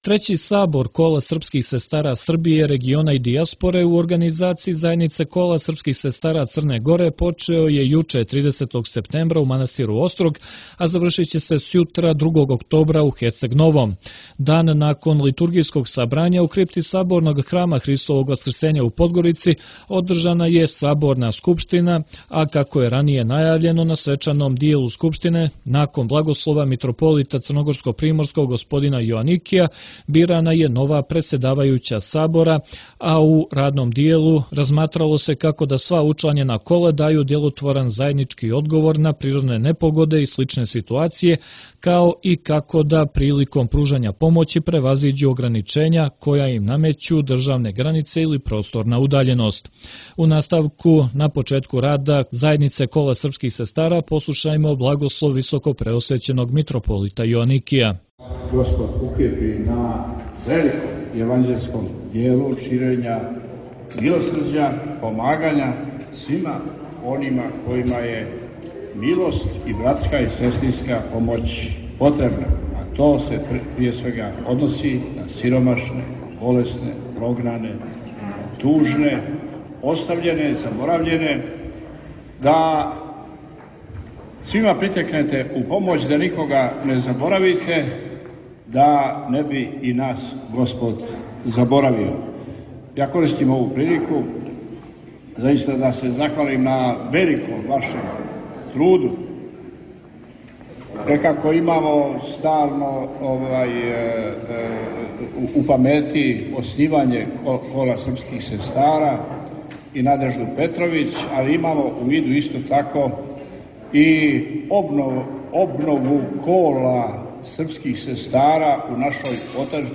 Након литургијског сабрања у Саборном храму Христовог Васкрсења у Подгорици, којим је почео трећи Сабор Кола српских сестара Србије, региона и дијаспоре, који се ове године одржава у Црној Гори, у крипти храма одржана је Саборна скупштина Кола